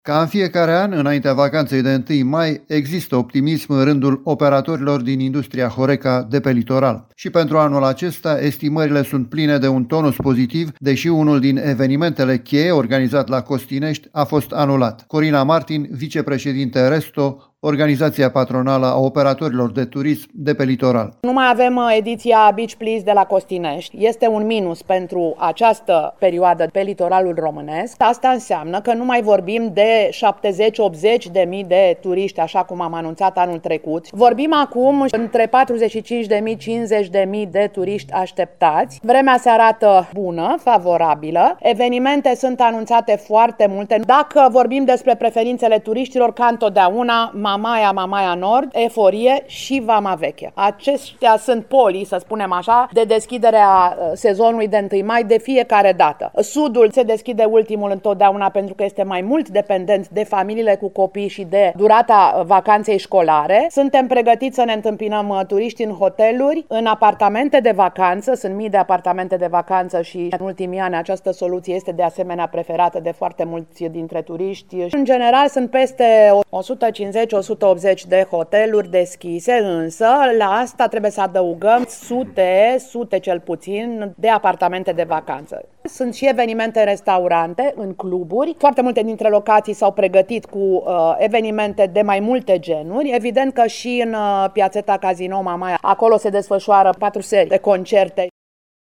Operatorii de turism de pe litoral și-au prezentat într-o conferință de presa oferta și estimările lor.